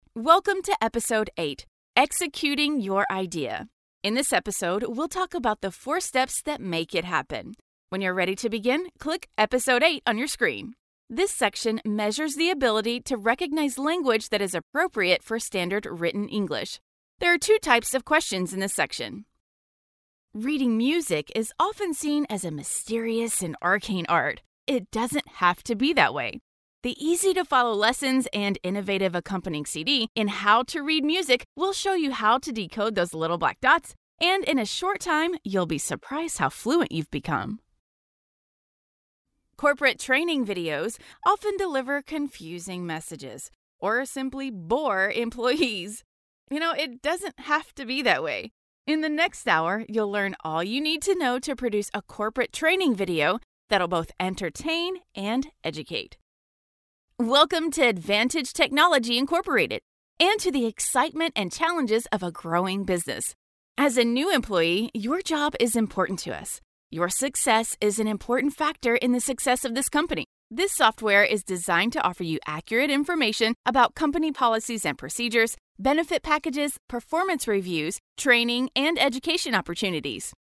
Female Voice Over, Dan Wachs Talent Agency.
Upbeat, Girl Next Door, Announcer.
eLearning